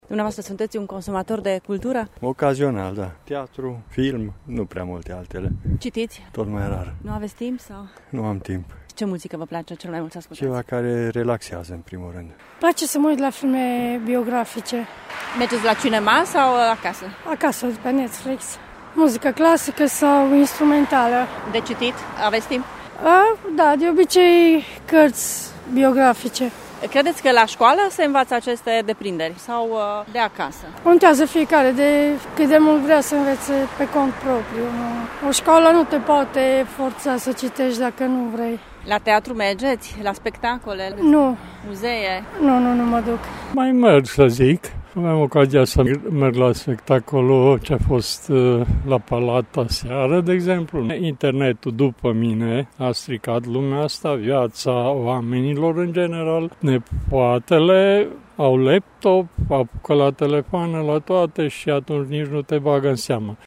Târgumureșenii recunosc că internetul este cel care influențează cel mai mult generația tânără în privința preferințelor și consumului de cultură: